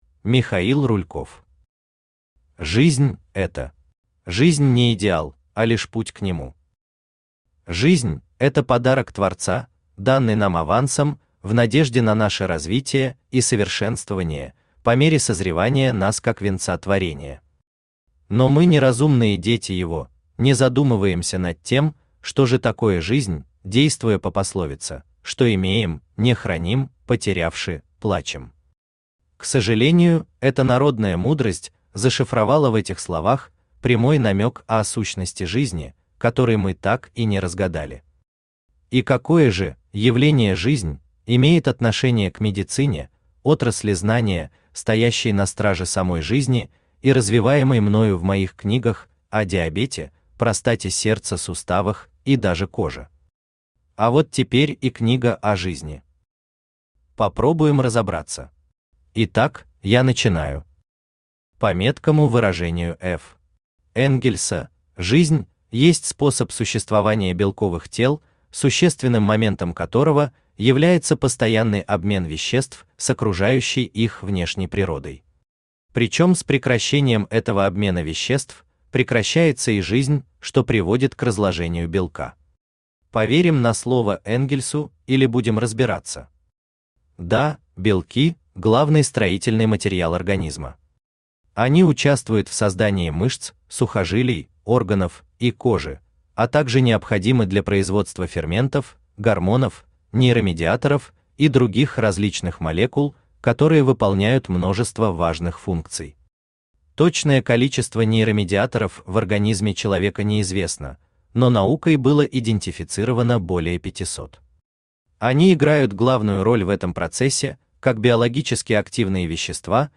Аудиокнига Жизнь – это | Библиотека аудиокниг
Aудиокнига Жизнь – это Автор Михаил Михайлович Рульков Читает аудиокнигу Авточтец ЛитРес.